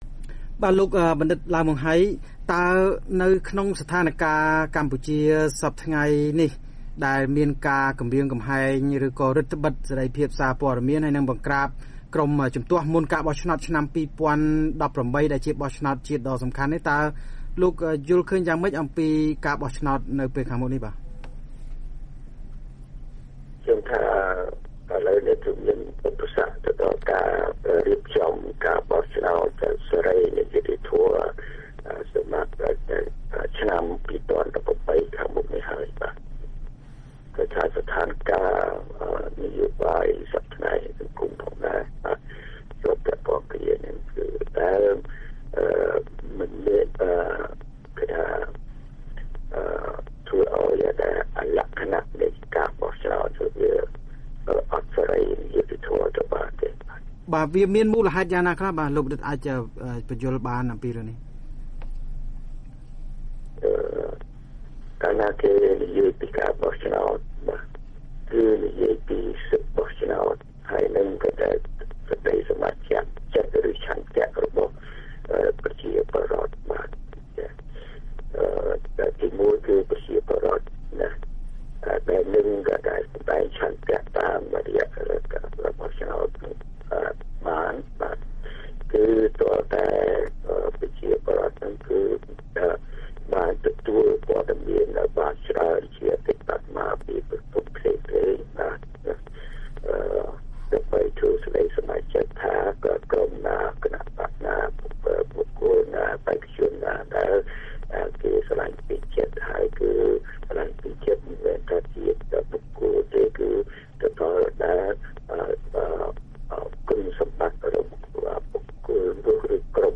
បទសម្ភាសន៍ VOA៖ អ្នកវិភាគពិភាក្សាពីការបោះឆ្នោតឆ្នាំ២០១៨ និងដំណោះស្រាយនយោបាយកម្ពុជា